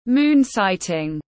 Ngắm trăng tiếng anh gọi là moon sighting, phiên âm tiếng anh đọc là /muːn ˈsaɪ.tɪŋ/
Moon sighting /muːn ˈsaɪ.tɪŋ/